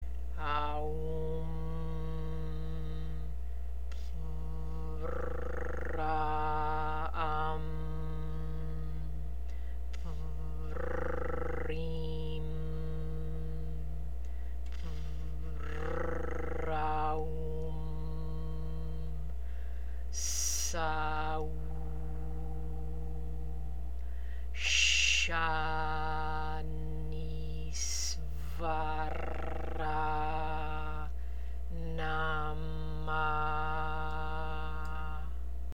МАНТРА ЗА САТУРН:
AАА-УУУ-MMM ٠ П-РР-AА-AА-MM ٠ П-РР-ИИ-MM ٠ П-РР-AА-УУ-MM ٠ ССА-УУУ ٠ Ш-АА-НИИ-СС-ВАА-РР-АА ٠ НАА-MAА
Артикулирайте "Р" правилно.
7 - Saturn Square Mantra.mp3